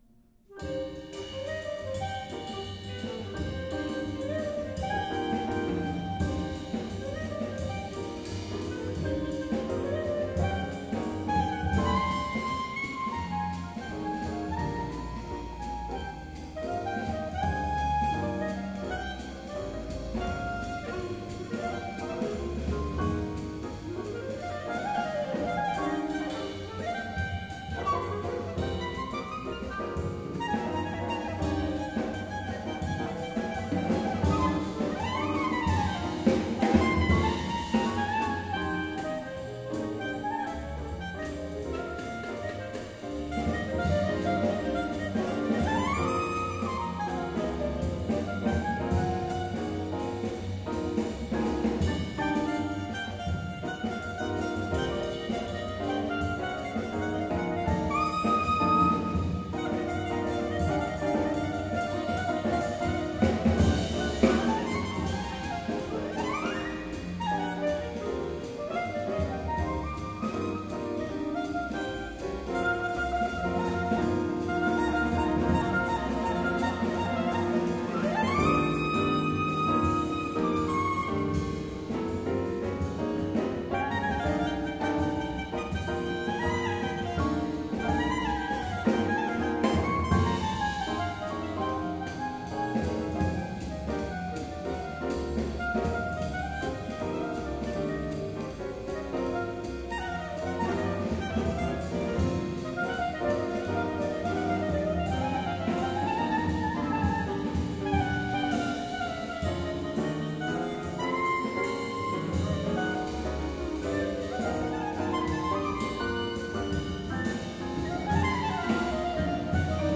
Please note: These samples are of varying quality.
Most were taken from live performances and are intended
for Clarinet and Orchestra
- Track 5 - M. Improvisation